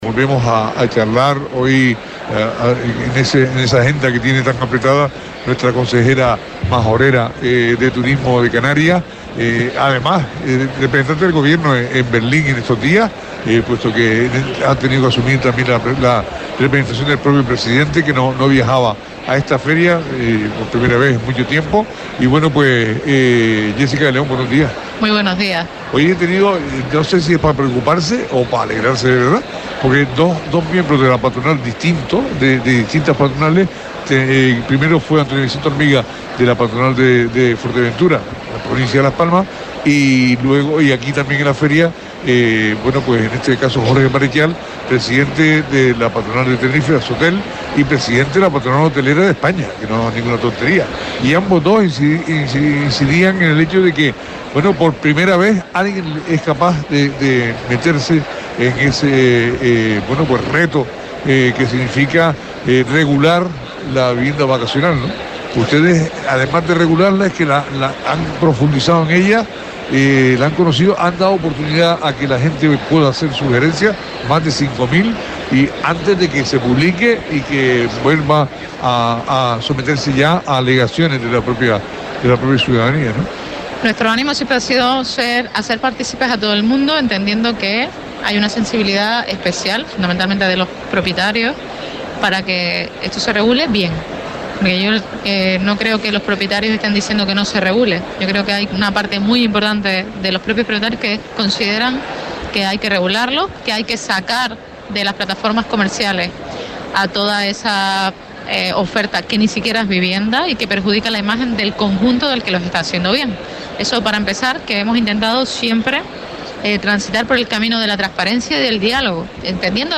La consejera de Turismo y Empleo del Gobierno de Canarias, Jéssica de León recordó esta mañana en el especial que Radio Sintonía realiza desde la ITB de Berlín 2024, que el turismo local dejo una facturación de 2.000 millones de euros el pasado año 2023, con lo que se convierte, con un 10%, en tercero […]